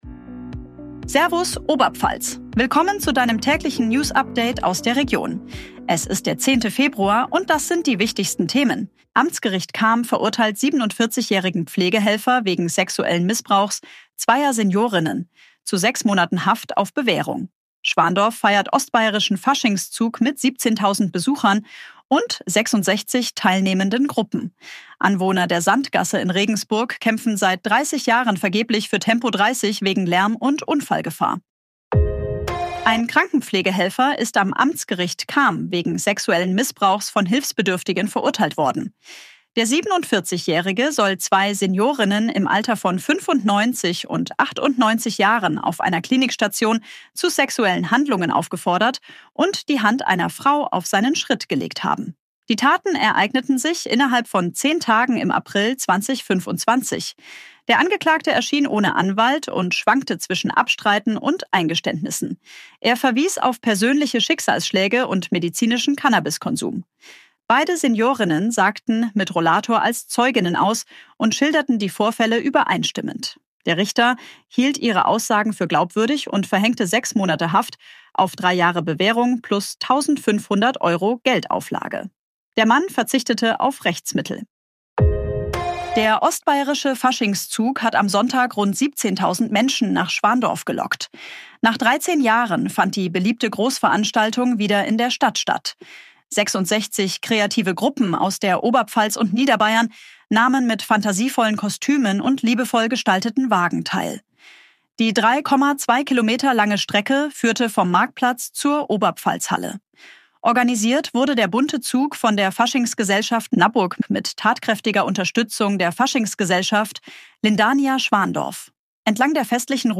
Tägliche Nachrichten aus deiner Region
Basis von redaktionellen Texten erstellt.